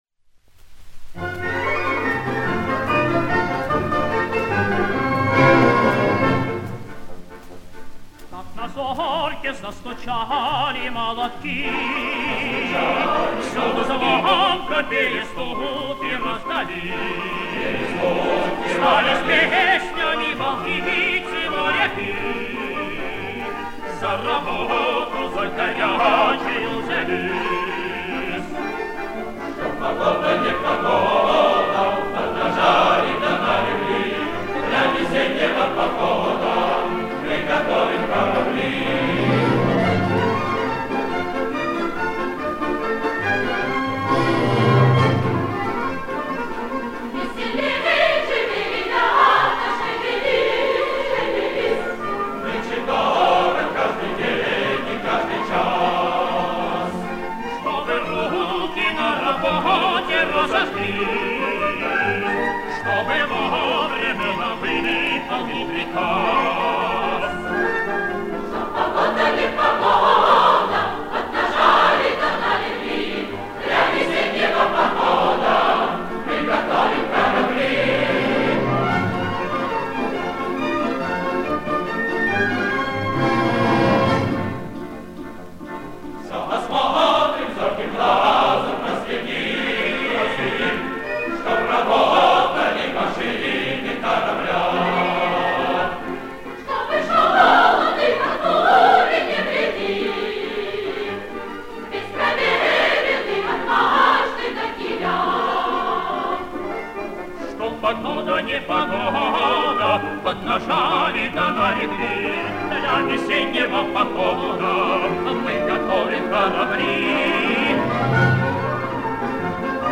Прославленная песня балтийских моряков в позднем исполнении